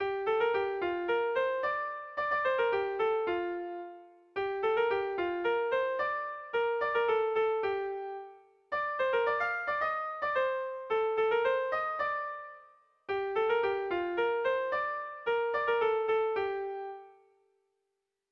Zortziko txikia (hg) / Lau puntuko txikia (ip)
A1A2BA2